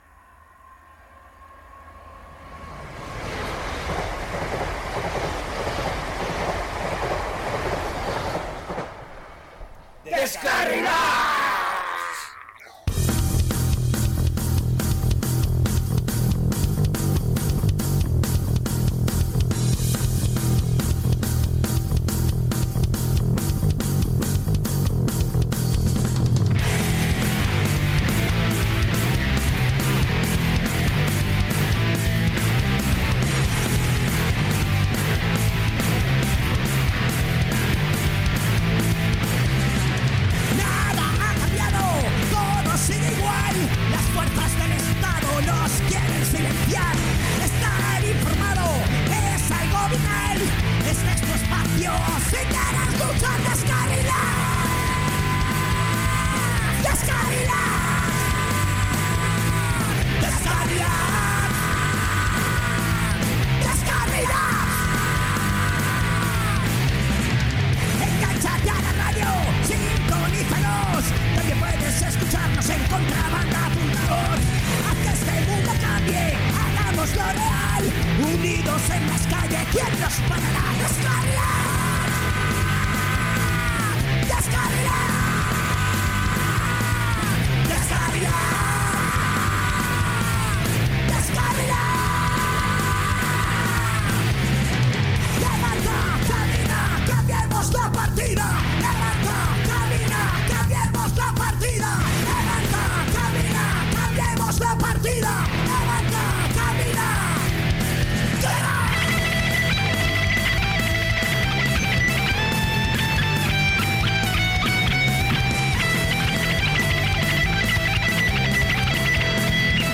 En el programa 75 de Deskarrilats musica y mas musica Australiana pequeña historieta del Punk y otras músicas de Australia.